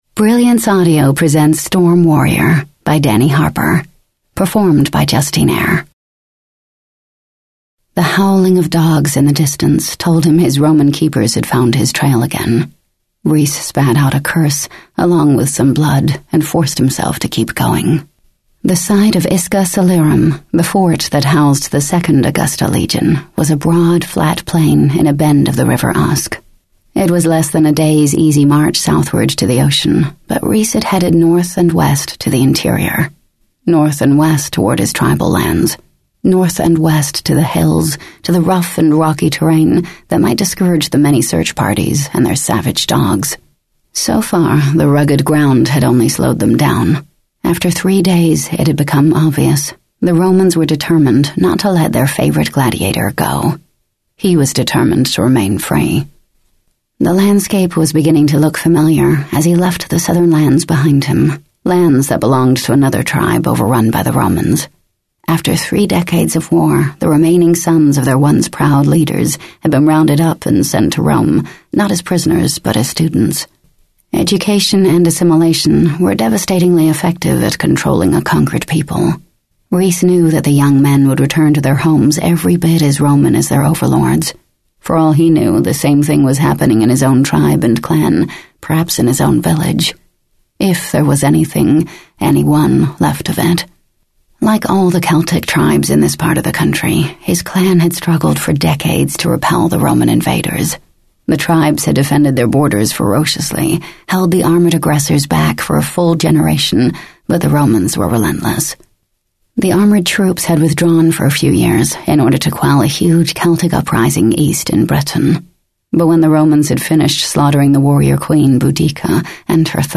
by Dani Harper | The Official Audiobook